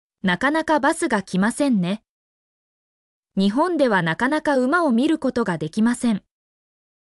mp3-output-ttsfreedotcom-38_HvRdH61D.mp3